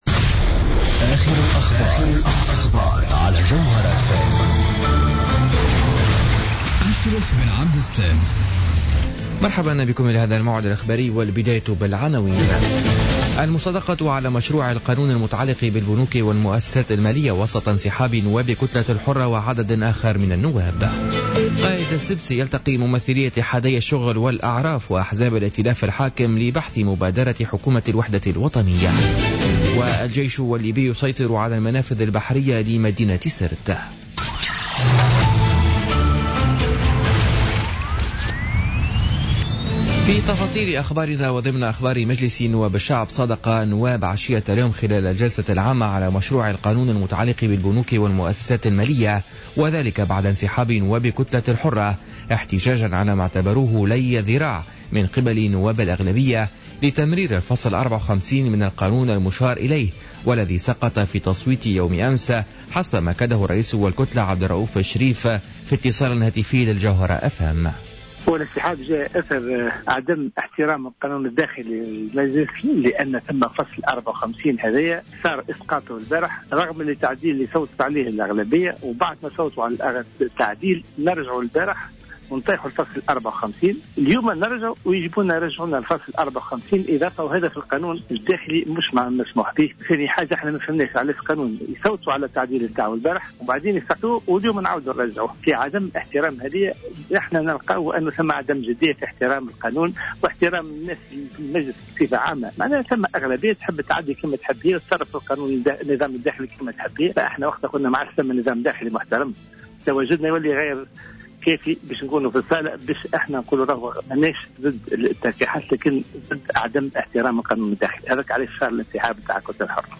Journal Info 17h00 du Jeudi 9 Juin 2016